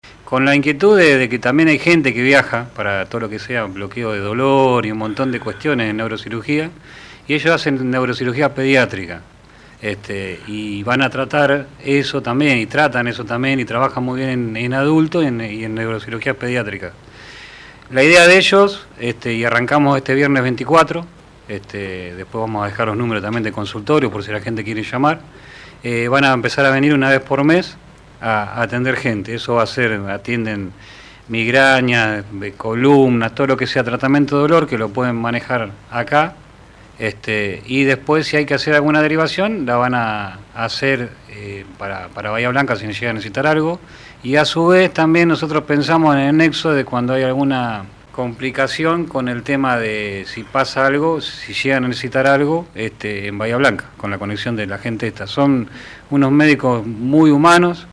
en nuestros estudios